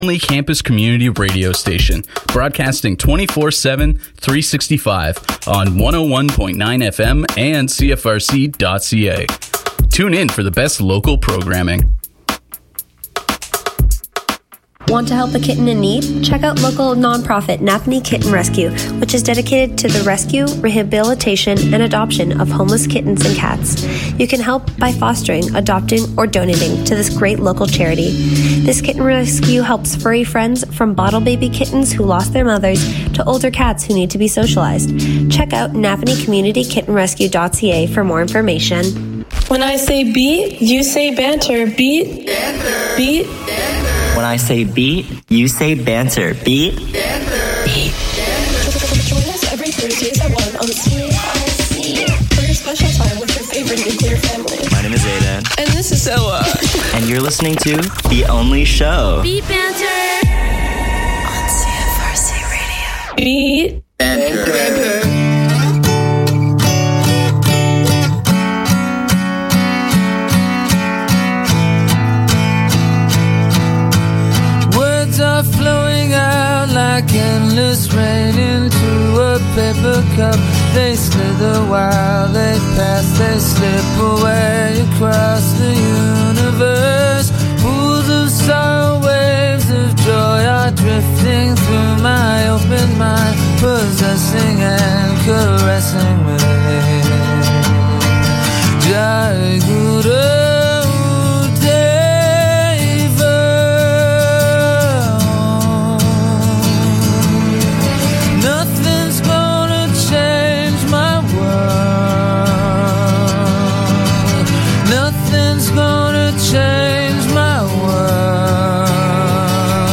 “I’ll Try Anything Once” is a radio show dedicated to exploring the vast and vibrant world of niche music genres, with each episode focusing on a different style—ranging from Midwest emo to dream pop and beyond.